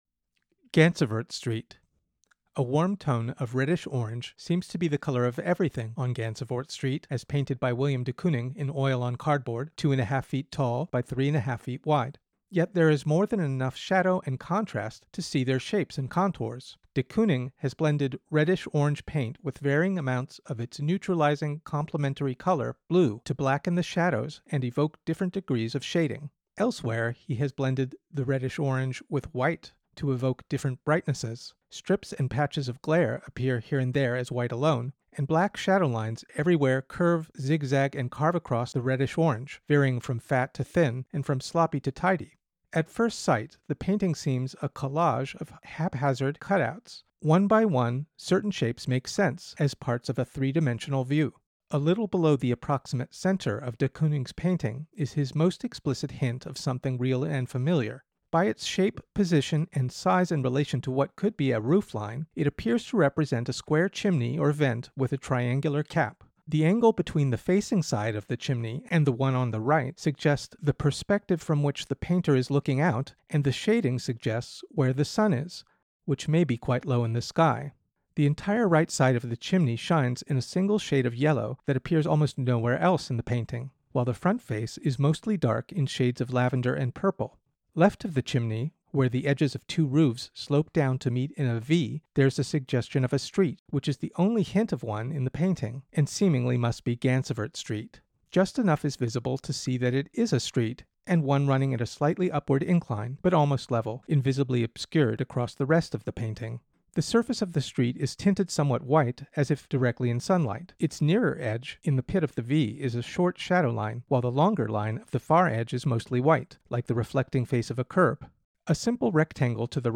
Audio Description (03:11)